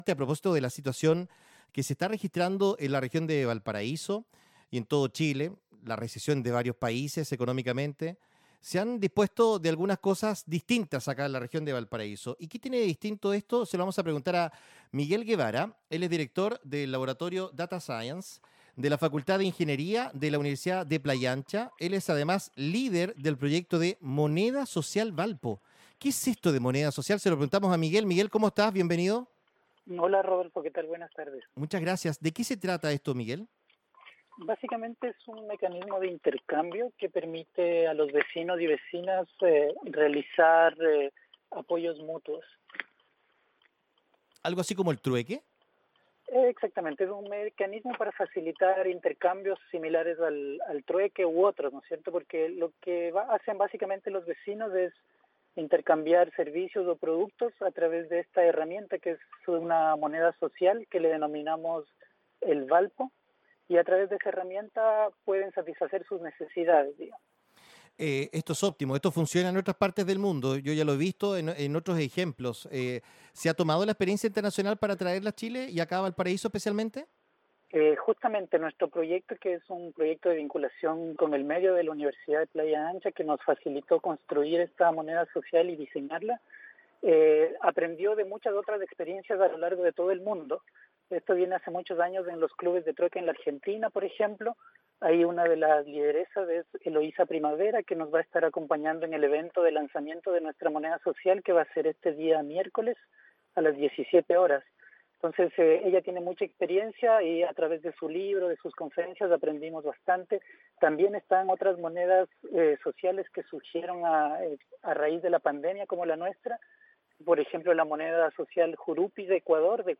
Moneda Social Valpo en radio Bío Bío, Valparaíso - Universidad de Playa Ancha - Facultad de Ingeniería